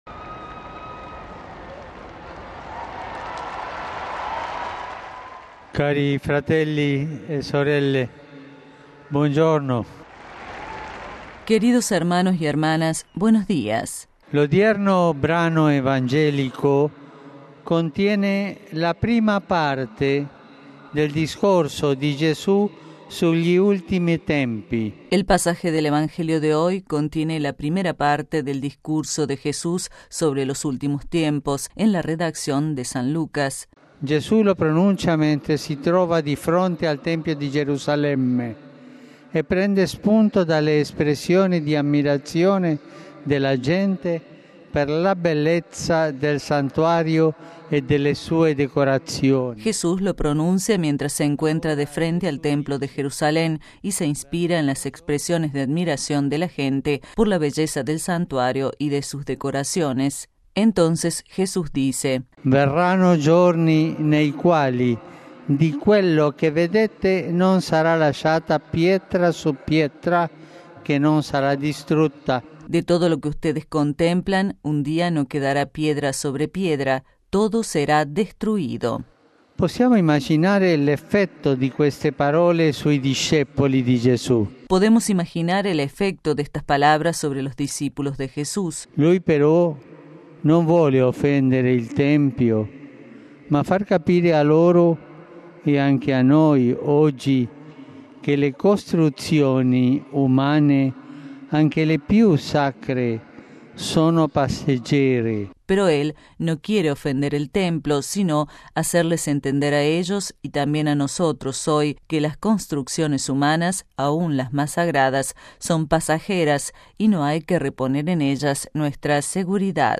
Ante una plaza de san Pedro repleta de peregrinos llegados de diversos países del mundo, el Pontífice basó su reflexión en el pasaje evangélico de Lucas en el que Jesús hablando a sus discípulos sobre los últimos tiempos, frente al templo de Jerusalén, les advierte sobre la condición efímera de las construcciones humanas y que no hay que poner en ellas nuestra seguridad.